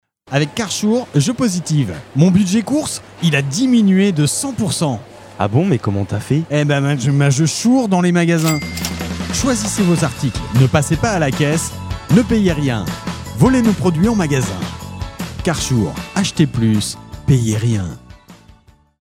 Fausses Pubs RADAR